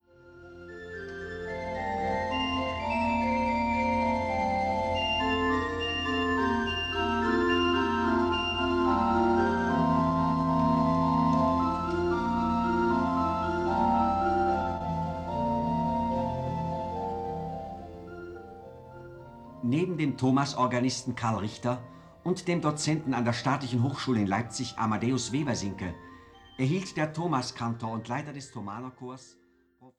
Karl Straube und Günther Ramin mit dem Thomanerchor Leipzig in frühesten Grammophon- und Rundfunkaufnahmen
Der Thomaskantor Günther Ramin